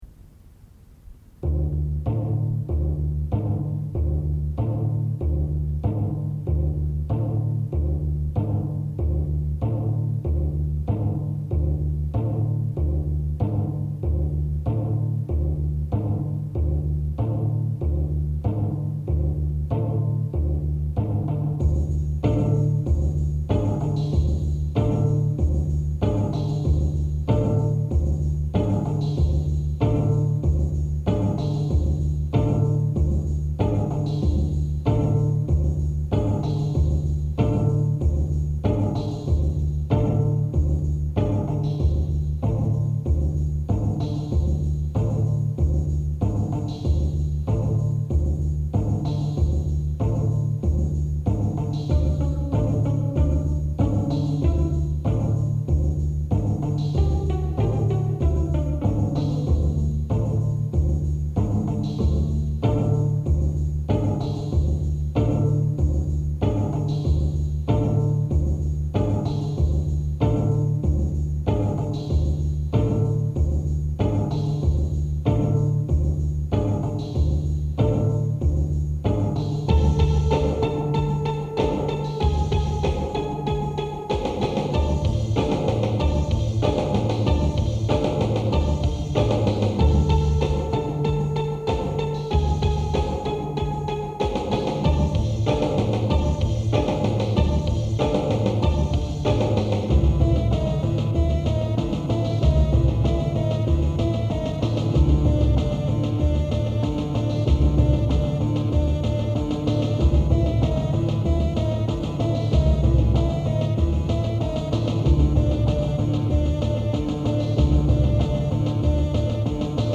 Tags: nightmares dj mixes rock n roll